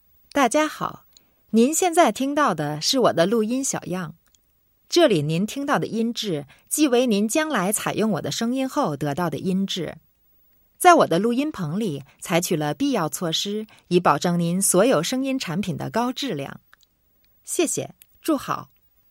Native Speaker Chinesisch Sprecherin, Synchronsprecherin
Female
Chinesisch Nachrichten Chinesisch Voiceover